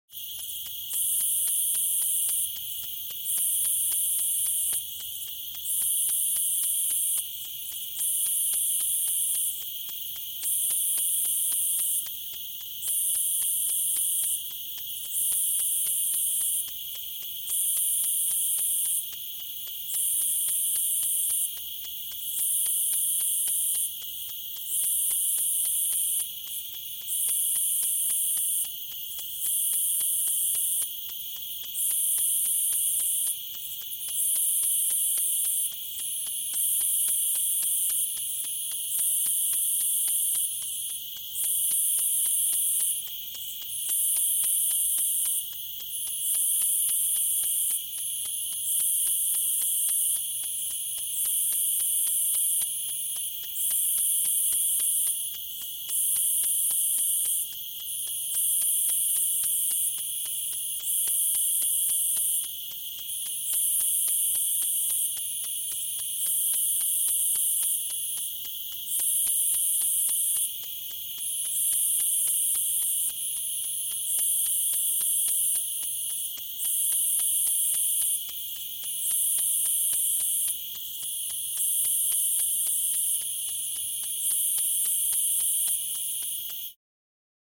دانلود صدای جیرجیرک 7 از ساعد نیوز با لینک مستقیم و کیفیت بالا
جلوه های صوتی